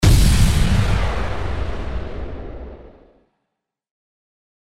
FX-464-IMPACT
FX-464-IMPACT.mp3